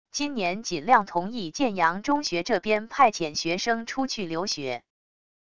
今年尽量同意建阳中学这边派遣学生出去留学wav音频生成系统WAV Audio Player